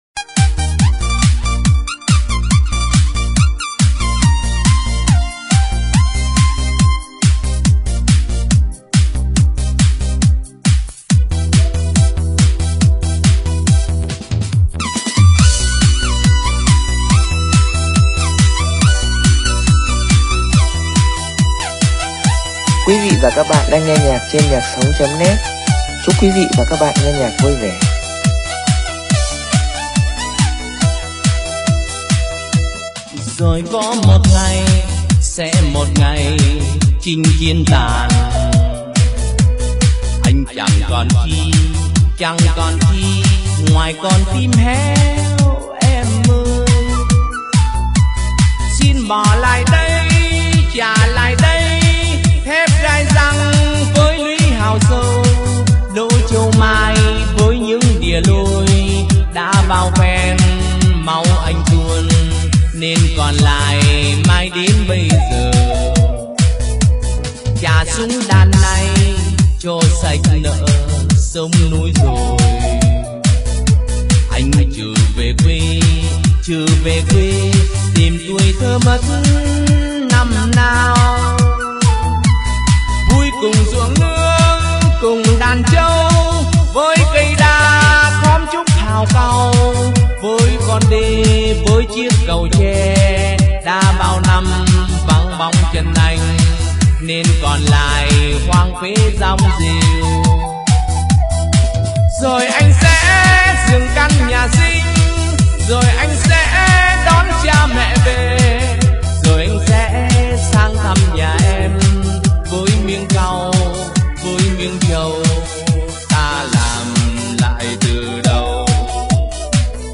Trang chủ  Nhạc Sống